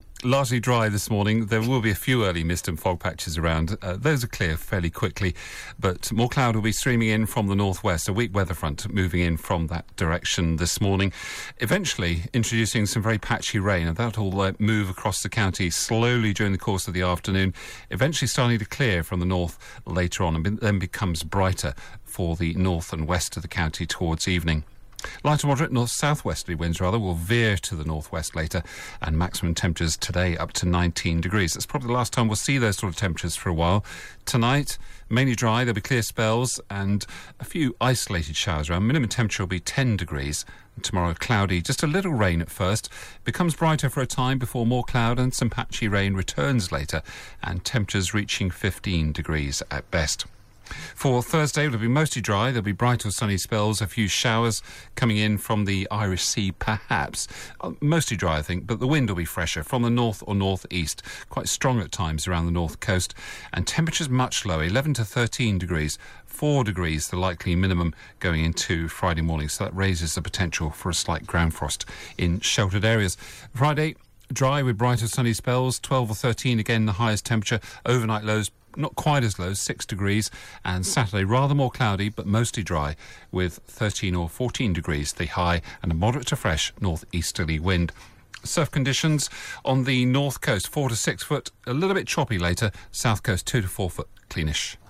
5 day forecast for Devon from 8.35AM on 8 October